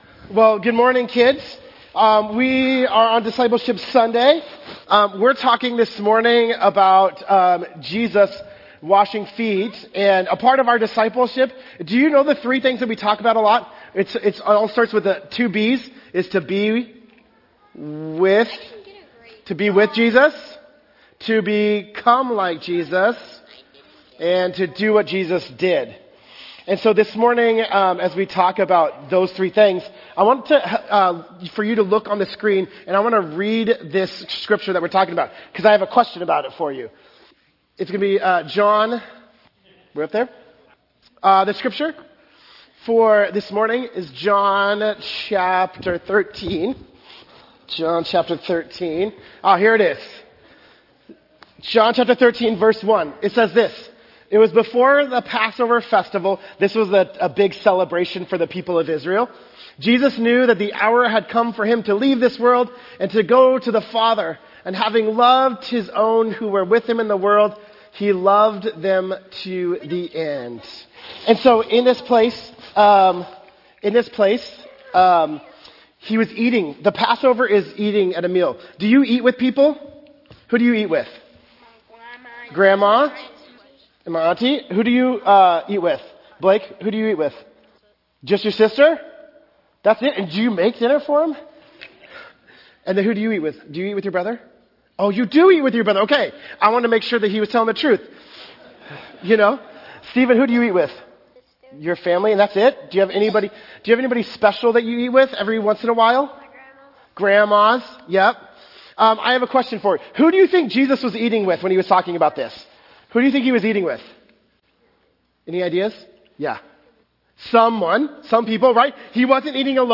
For Discipleship Sunday, we’re gathering as one church family—from ages 3 to 103—to practice discipleship the way it often happens best: in conversation, in wondering, and in processing together.